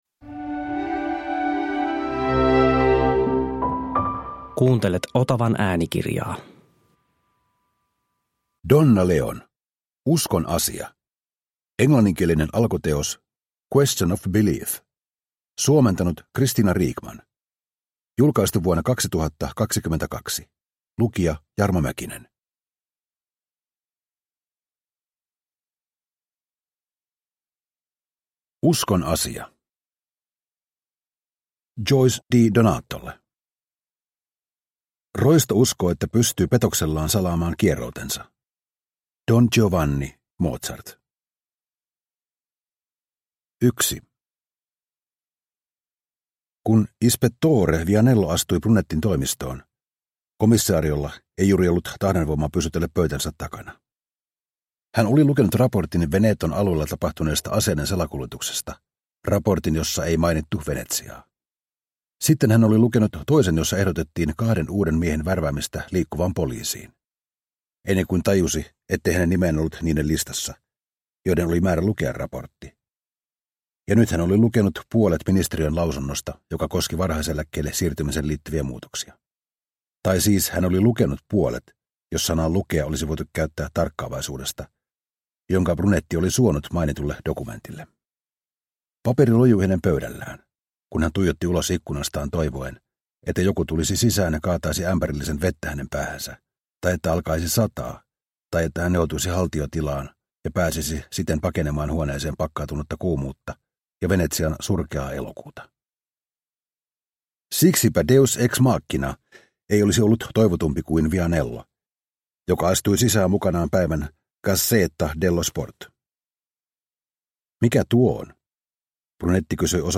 Uskon asia – Ljudbok – Laddas ner